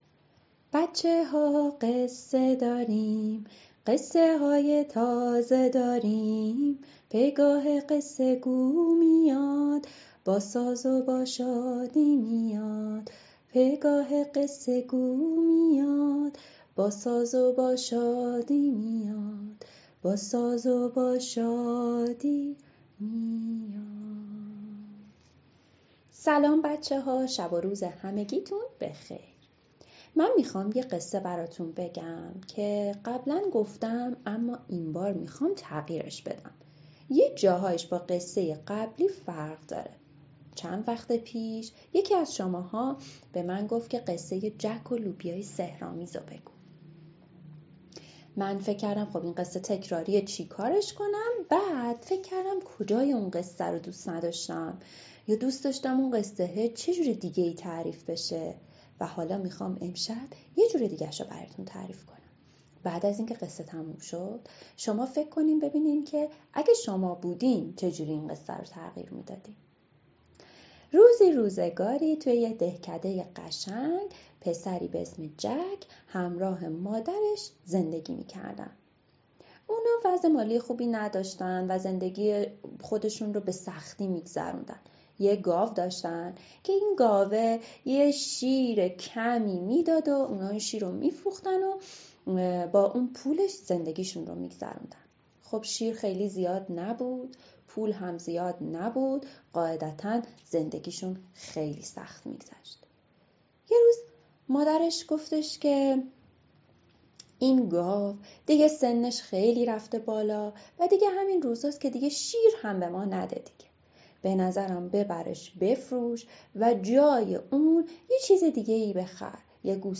قصه صوتی کودکان دیدگاه شما 3,510 بازدید